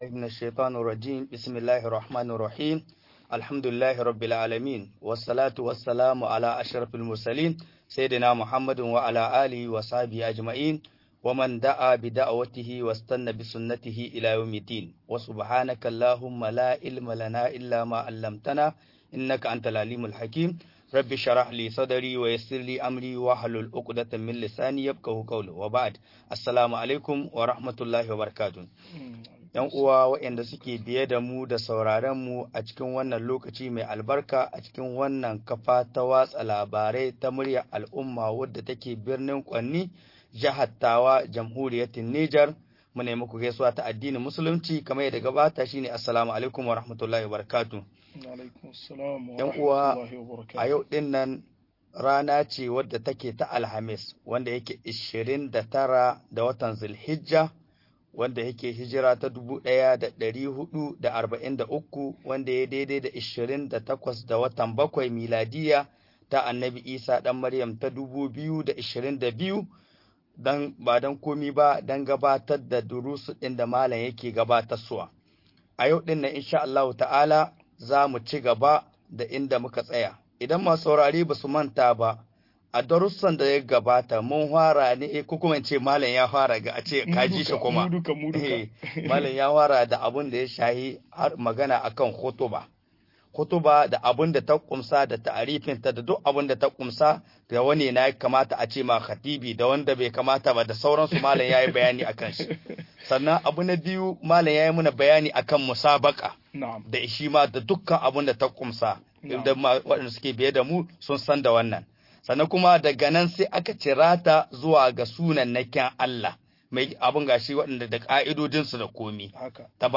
Sunayen Allah da siffofin sa-23 - MUHADARA